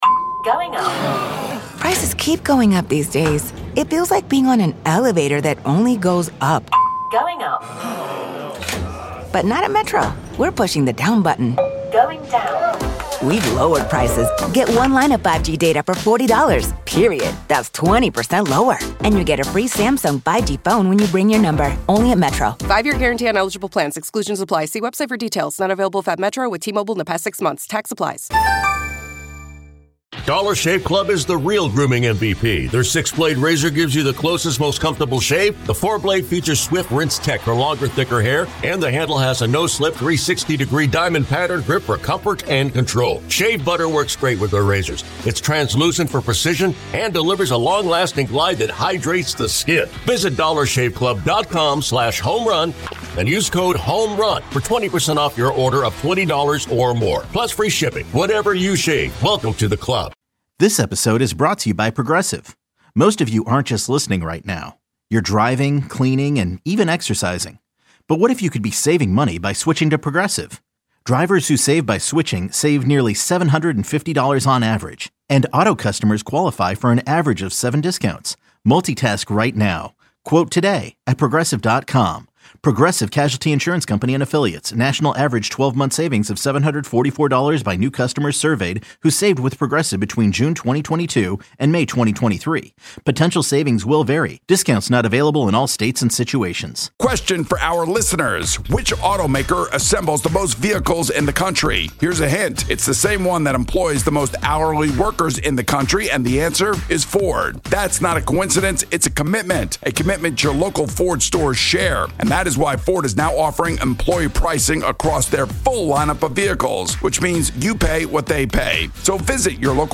1 Best of 92-9 the Game Football Interviews 1:17:13